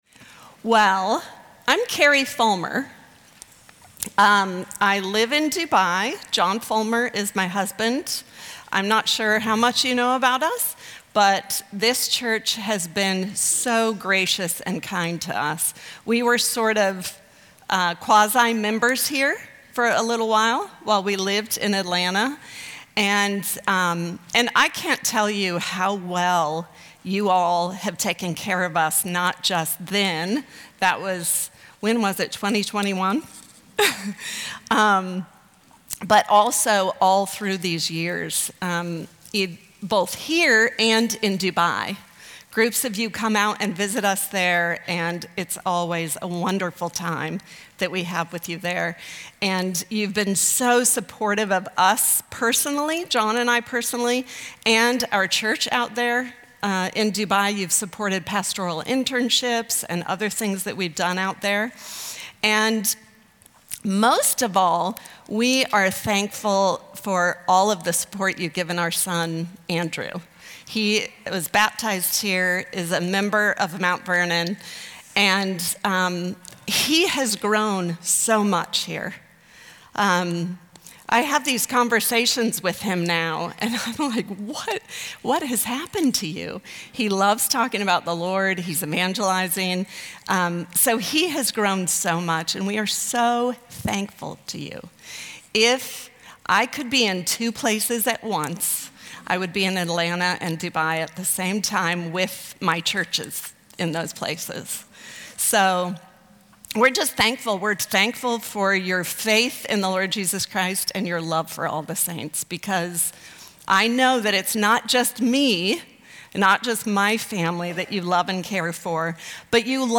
Audio recorded at MVBC’s 2025 Women’s Retreat.